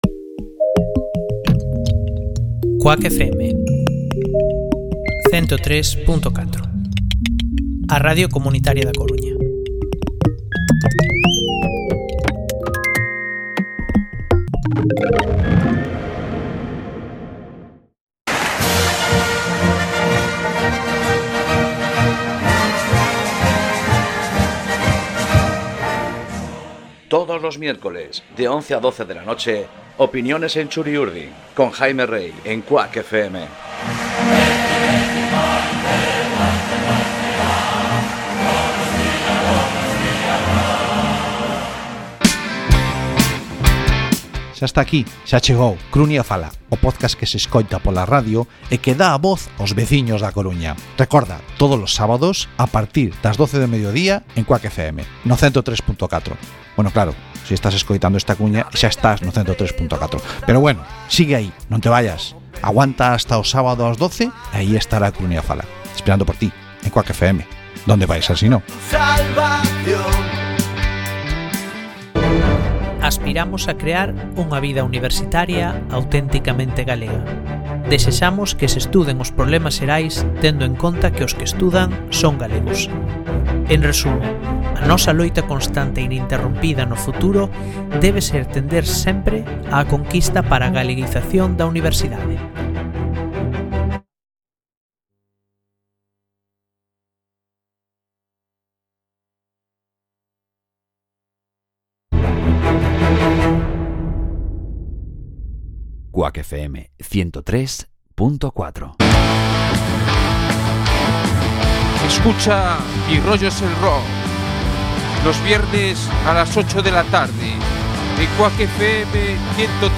Nos visitan en el estudio